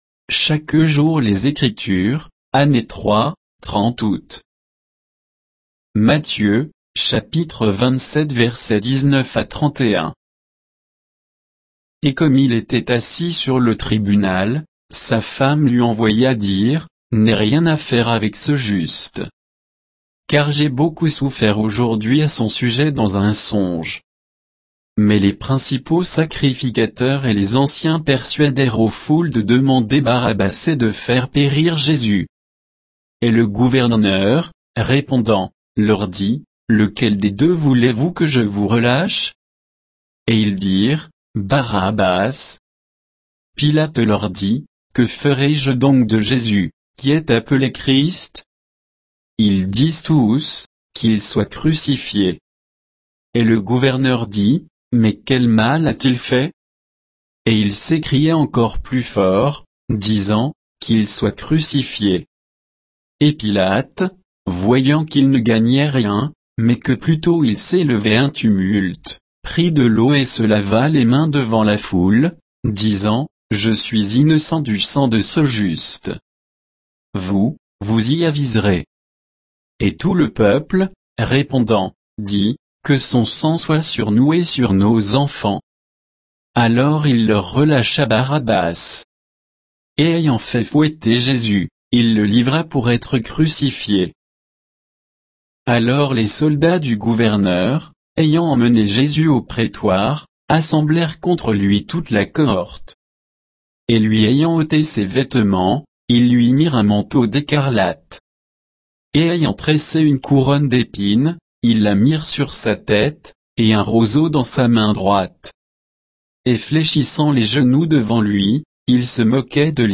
Méditation quoditienne de Chaque jour les Écritures sur Matthieu 27, 19 à 31